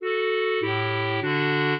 clarinet
minuet3-11.wav